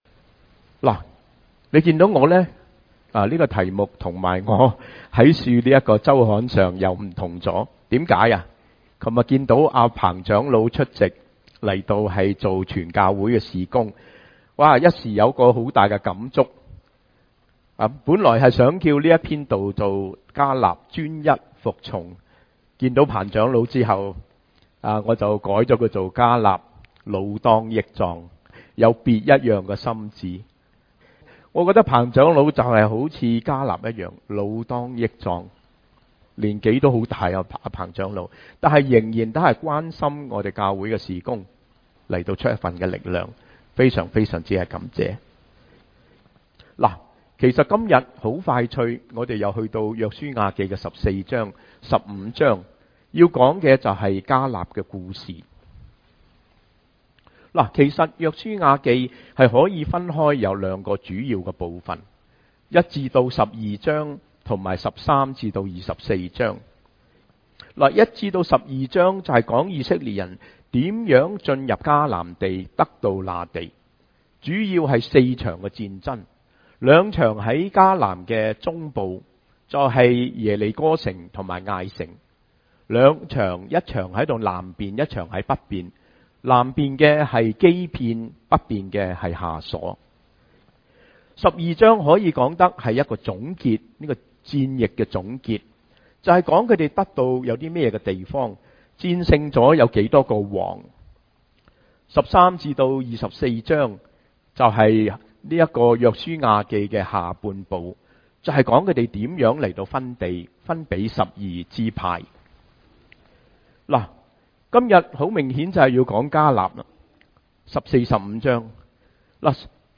cantonese sermons, sermons in cantonese, chinese sermons, sermons in chinese, christian sermons, cantonese sermon, sermon in cantonese, chinese sermon, sermon in chinese
preached at Community Christian Alliance Church (CCAC)